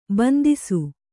♪ bandisu